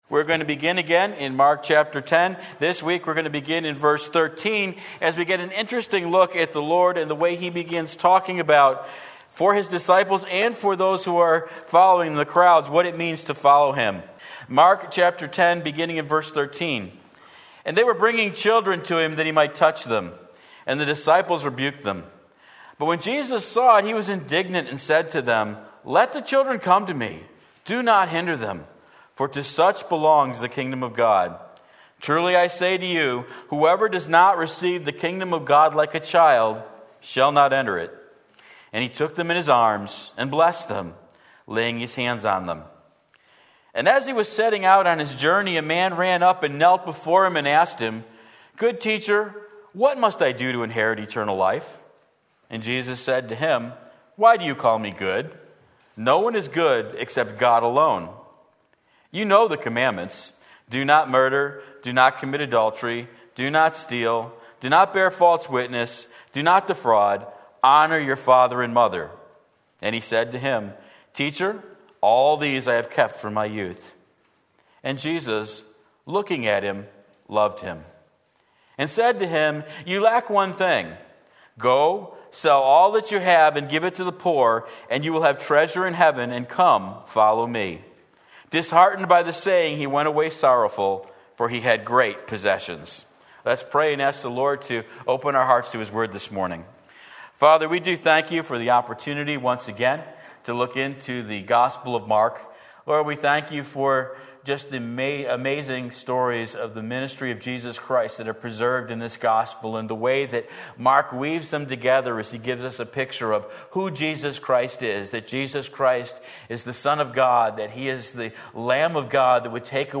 Sermon Link
Mark 10:13-22 Sunday Morning Service